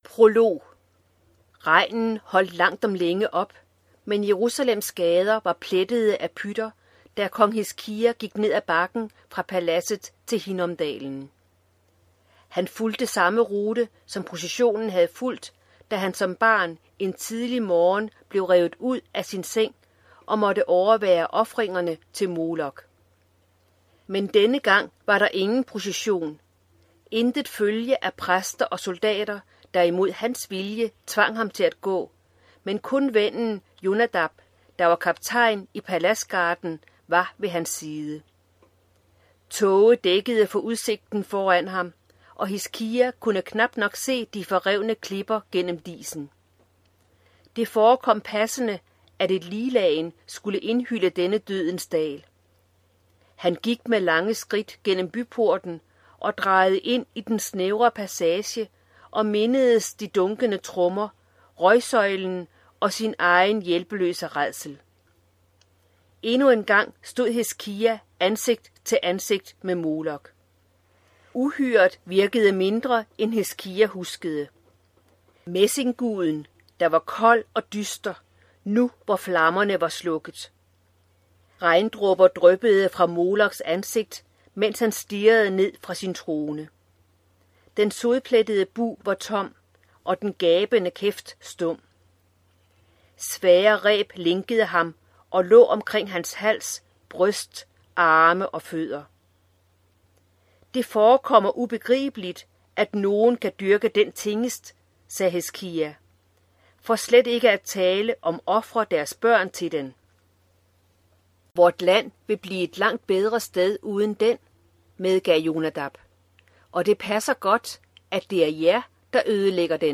Hør et uddrag af En sang om kærlighed En sang om kærlighed Konge Krøniken II Format MP3 Forfatter Lynn Austin Lydbog E-bog 99,95 kr.